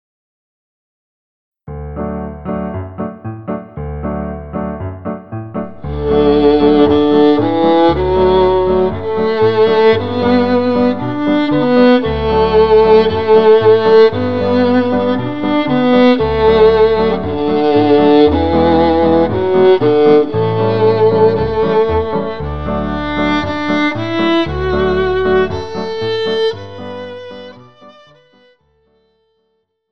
Besetzung: Viola